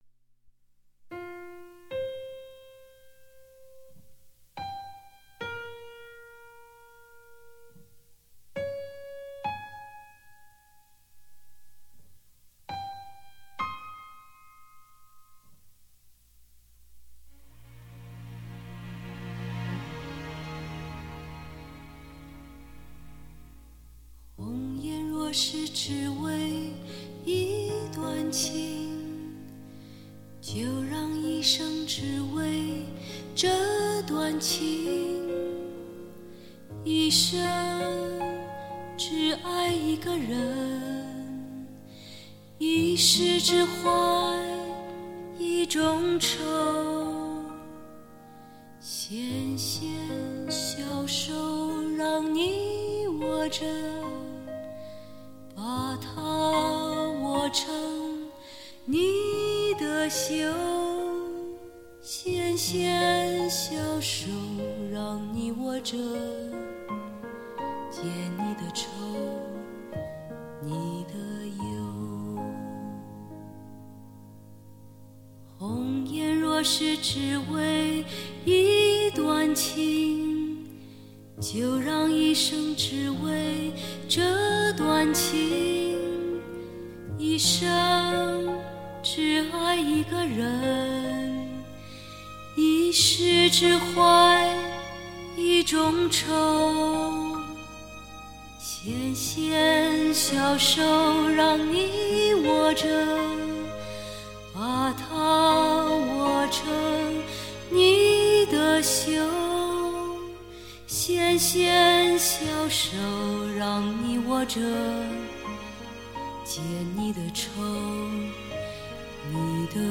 蔓延着无尽孤独感伤 麻木惫懒的情绪
蔓延着无尽孤独感伤、麻木惫懒的情绪。